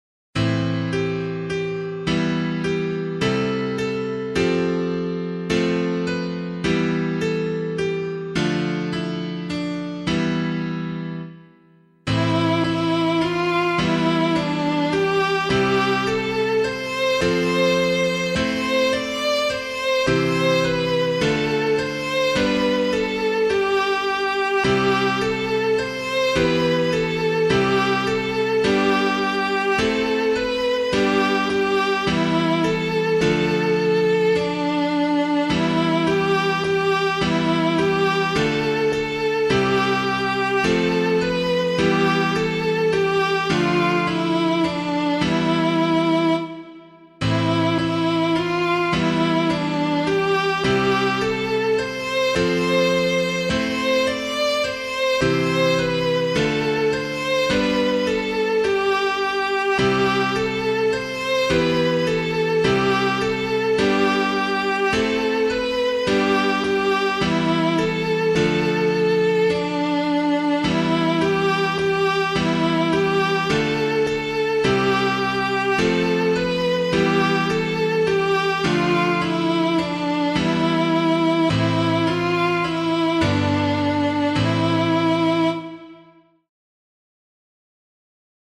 Composer:    Chant, mode III
piano